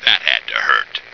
flak_m/sounds/male2/int/M2thathadtohurt.ogg at d2951cfe0d58603f9d9882e37cb0743b81605df2